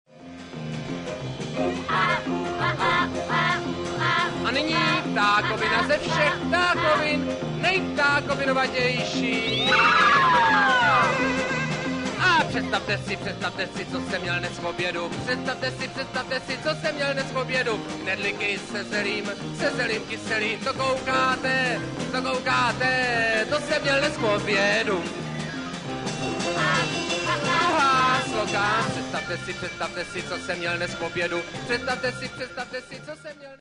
actor, stage director, singer and composer.